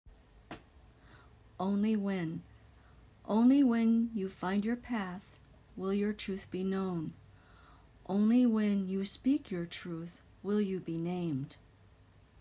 I added an audio file of me reading it.